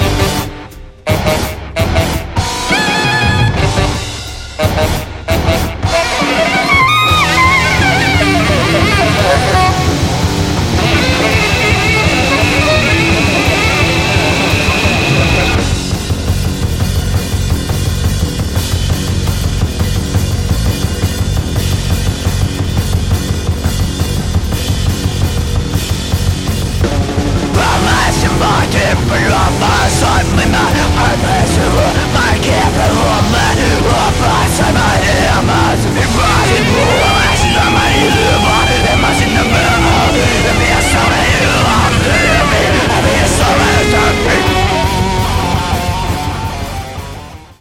best encapsulate the hardcore punk feel